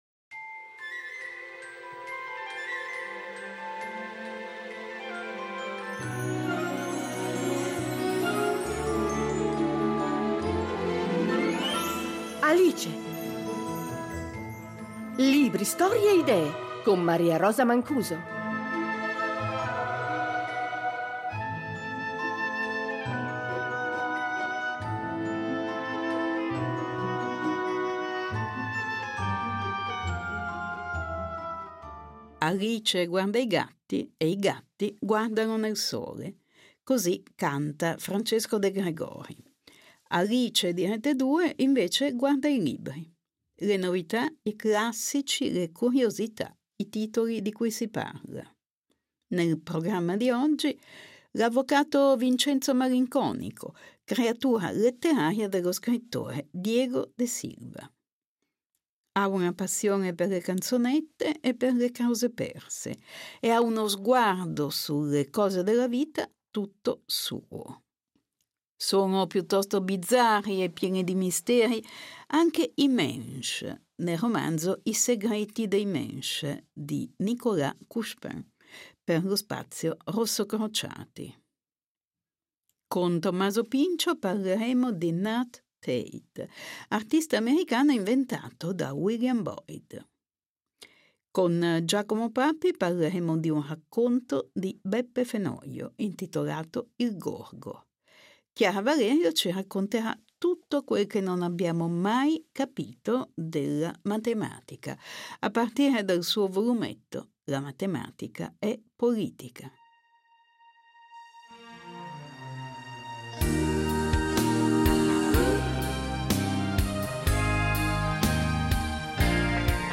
Nell’intervista d’apertura avremo Diego De Silva , con "I valori che contano" , ultimo romanzo che ha per protagonista l’avvocato Vincenzo Malinconico. Parleremo di un falso letterario-artistico, con David Bowie in una parte non secondaria.